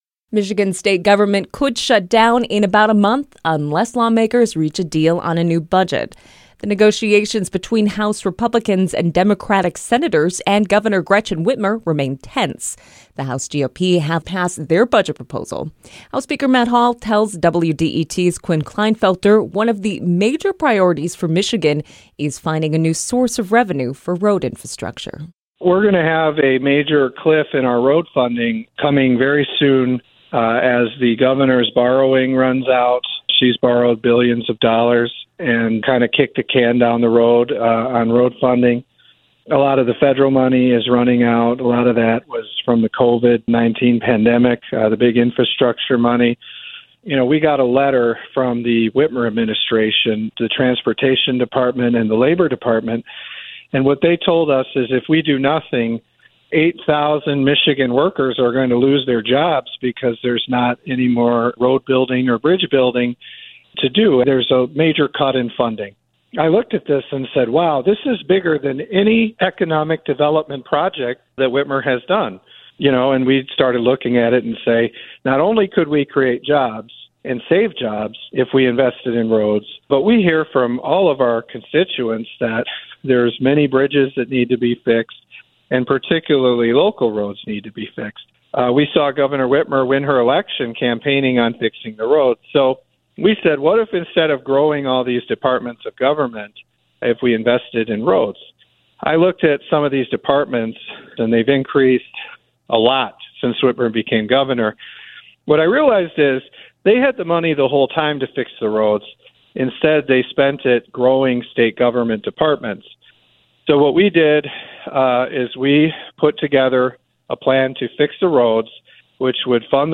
The following interview has been edited for clarity and length